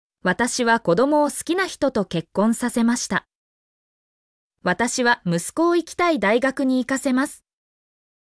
mp3-output-ttsfreedotcom-3_TTPyIPVS.mp3